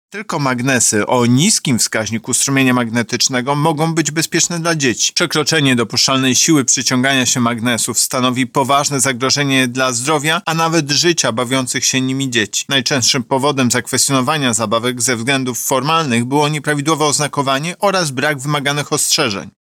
Kupując coś najmłodszym kierujmy się zdrowym rozsądkiem i odrobiną wyobraźni – mówi Tomasz Chróstny, prezes Urzędu Ochrony Konkurencji i Konsumentów: